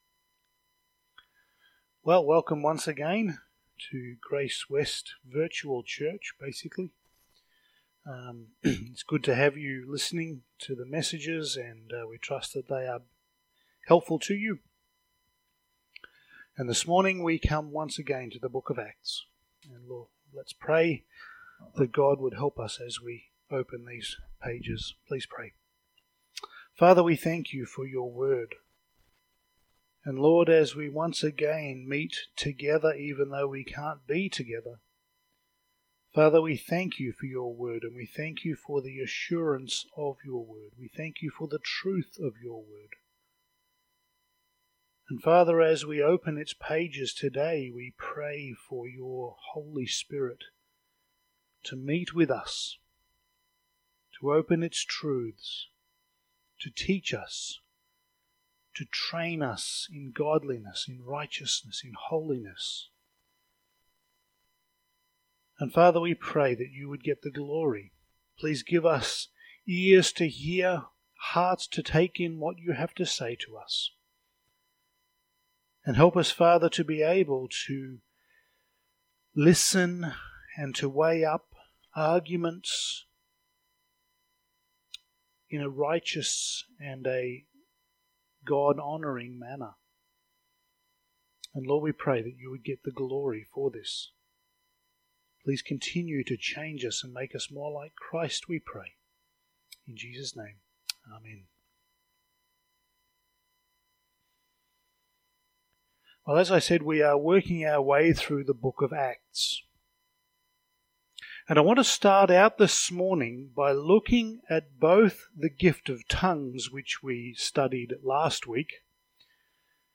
Passage: Acts 3:1-10 Service Type: Sunday Morning